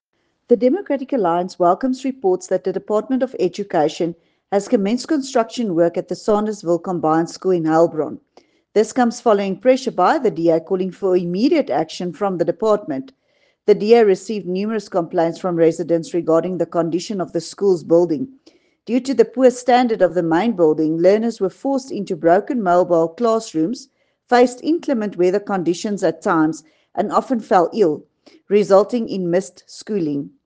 English and Afrikaans soundbites by Dulandi Leech MPL and